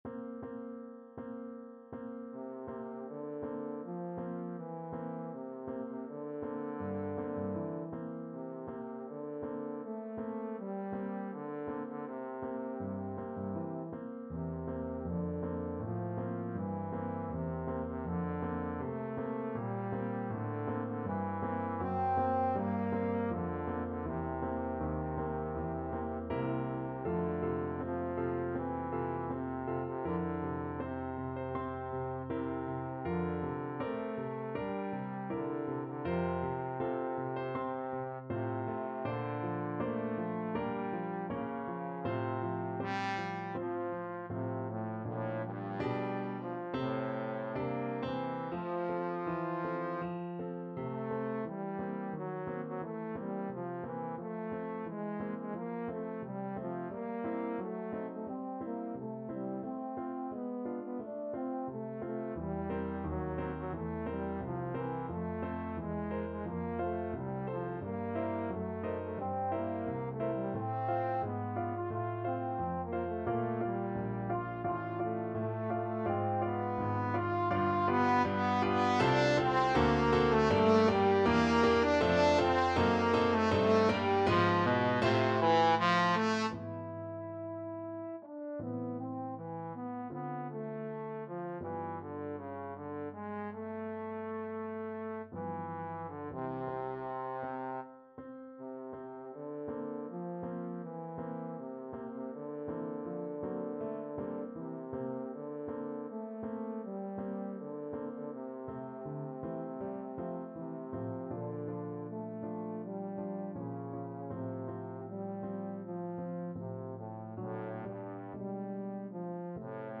Trombone
F major (Sounding Pitch) (View more F major Music for Trombone )
Andante espressivo
Classical (View more Classical Trombone Music)